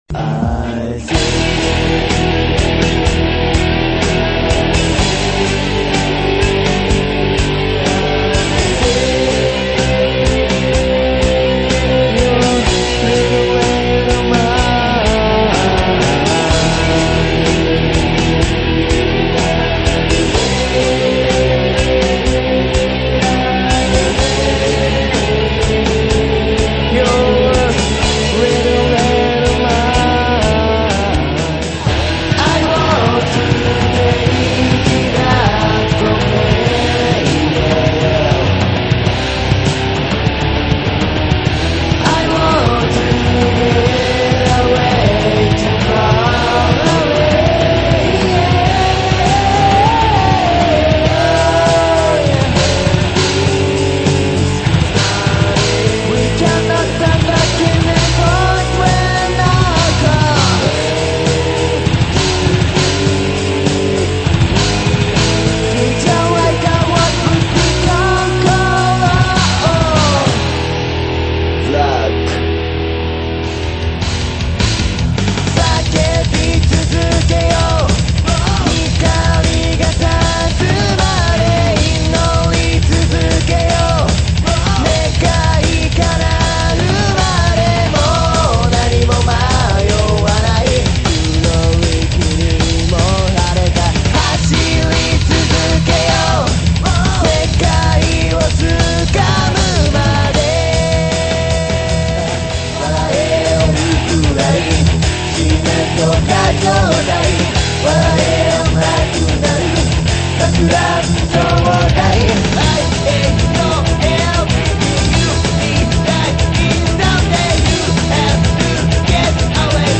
７弦っつったら普通のギターの２音半下の音が出るわけで、否応なしにヘヴィーな曲になっちゃいました。